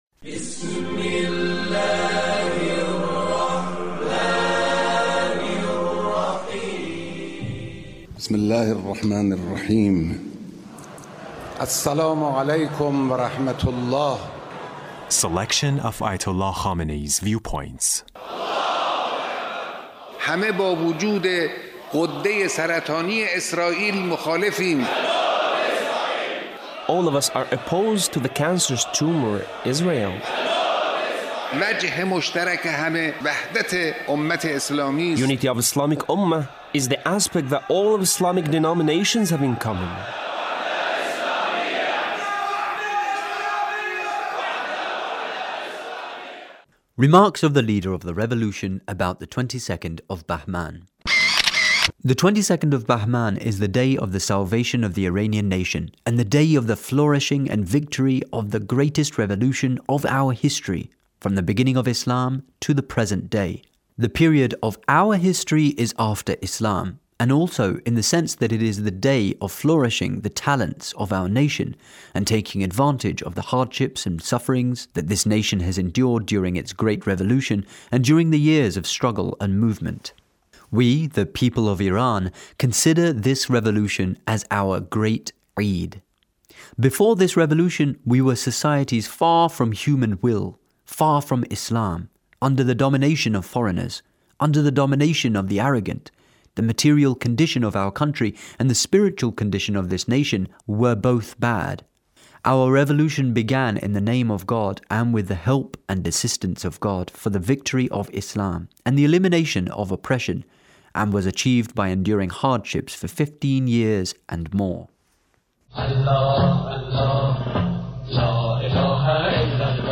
The Leader's speech on Revolution